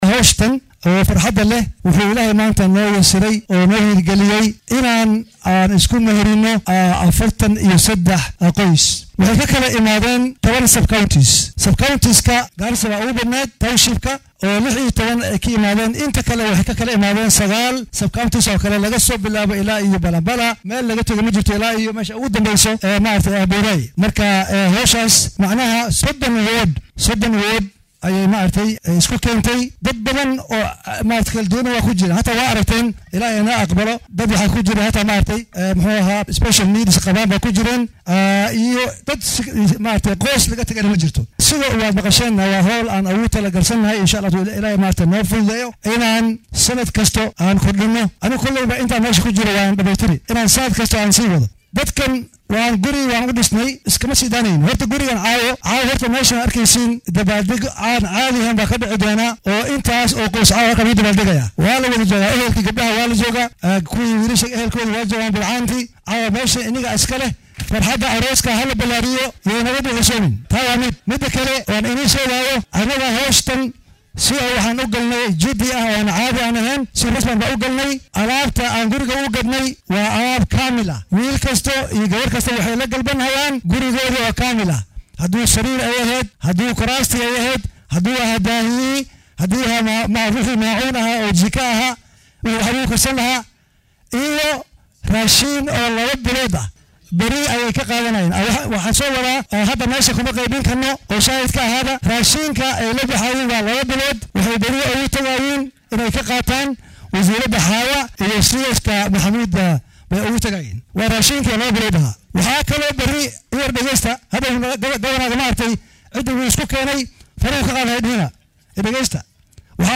Waxaa magaalada Garissa lagu qabtay aroos wadareed uu maalgeliyay barasaabka dowlad deegaankaasi Nathiif Jaamac Aadan halkaasi oo la isku meheriyay 43 wiilal iyo gabdho ah. Guddoomiyaha Garissa Nathiif Jaamac ayaa faahfaahin ka bixiyay barnaamijkan iyo taageerada kale ee ay siinayaan qoysaska ay dhisayaan.